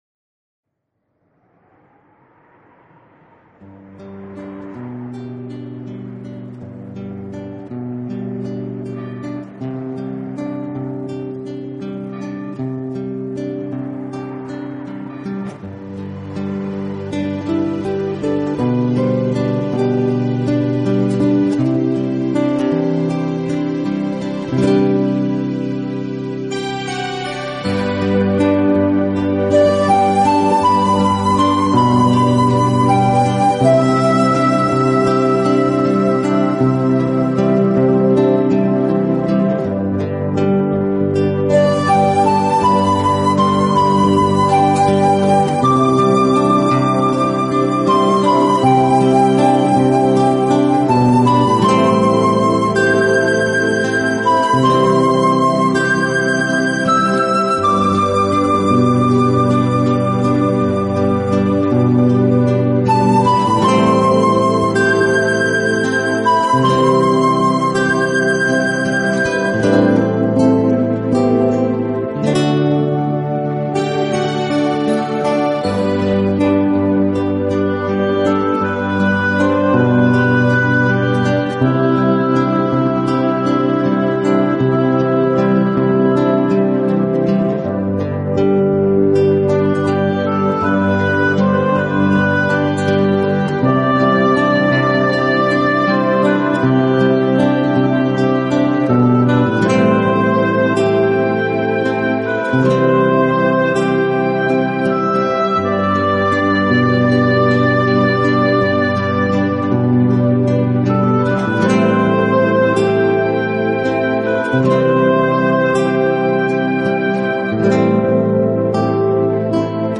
从其不落俗套编曲，精简的乐器配置，使每首曲子都呈现出清新的自然气息。